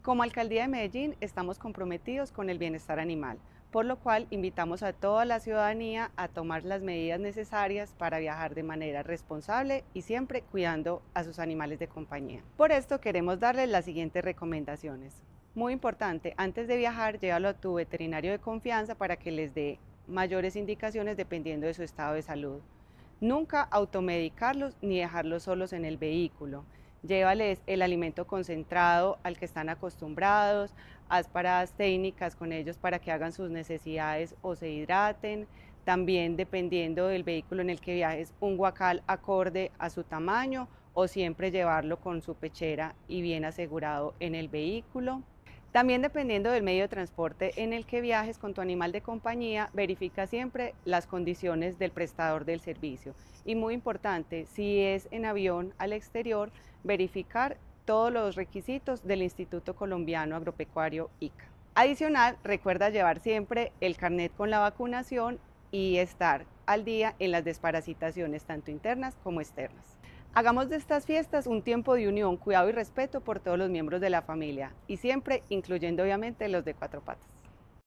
Palabras de Elizabeth Coral, subsecretaria de Protección y Bienestar Animal